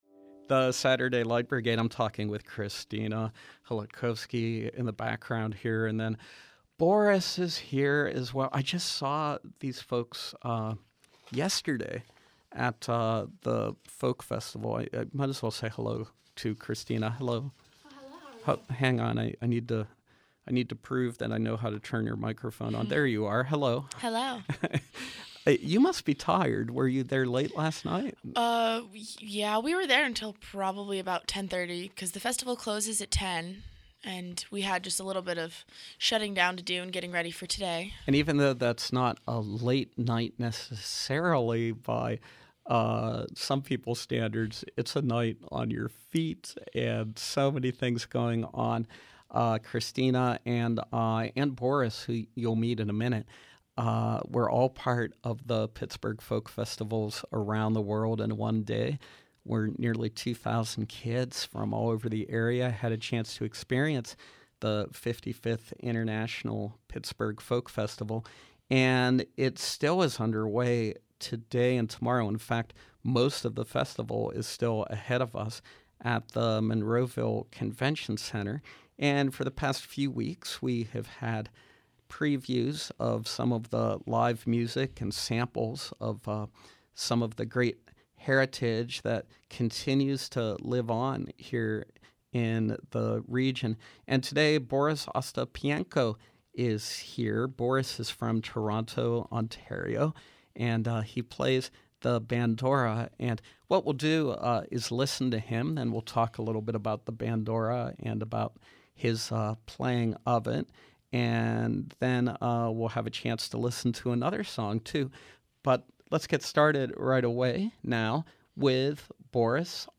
Live Music
Bandura